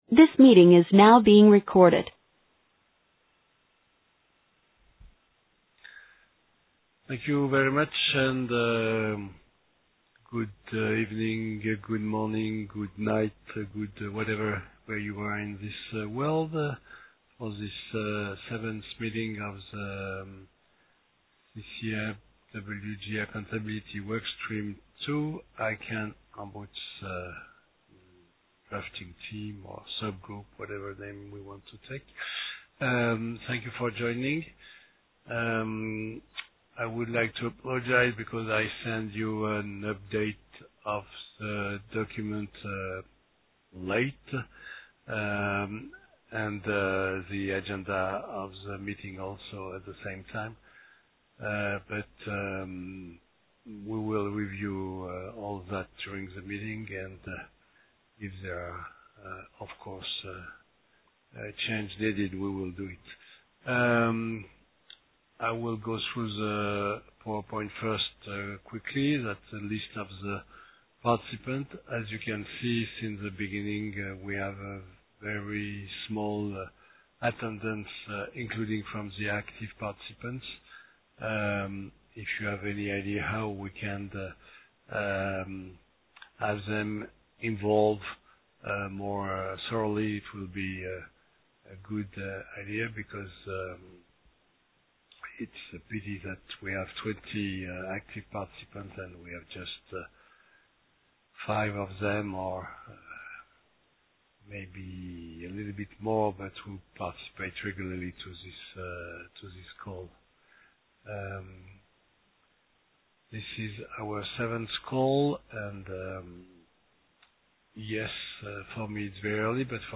Roll Call / Apologies – Welcome – Opening Remarks